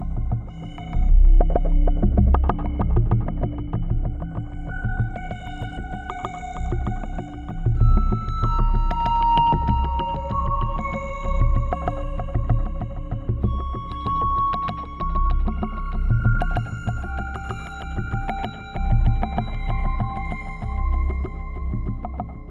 Creepy Alien Music Effect.